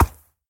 Sound / Minecraft / mob / horse / soft6.ogg